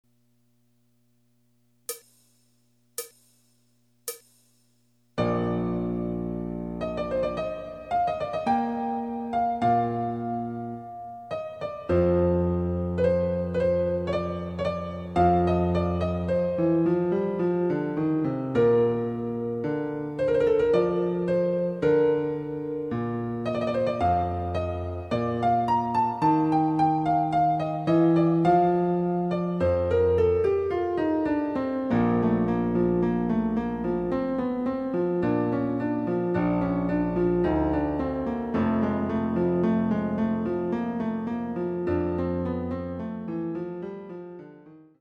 ★フルートの名曲をピアノ伴奏つきで演奏できる、「ピアノ伴奏ＣＤつき楽譜」です。
試聴ファイル（伴奏）